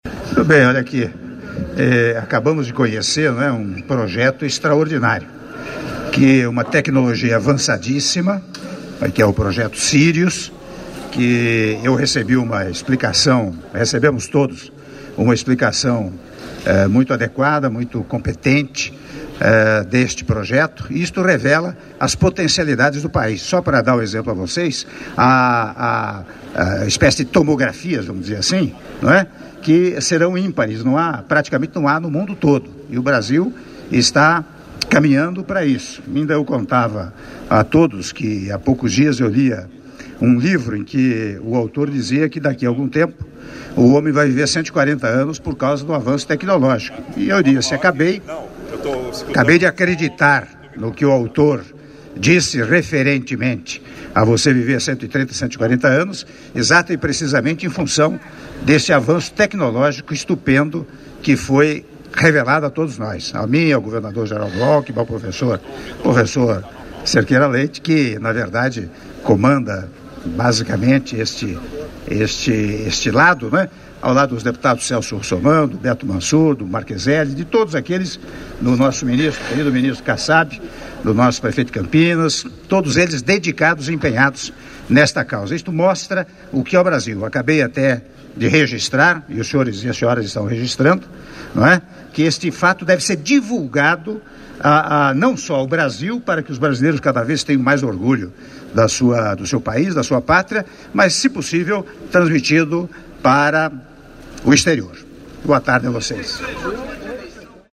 Áudio da declaração à imprensa concedida pelo Presidente da República, Michel Temer, após visita às obras da Fonte de Luz Síncrotron de Quarta Geração Sirius - Campinas/SP - (01min47s)